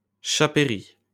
Chapeiry (French pronunciation: [ʃapɛʁi]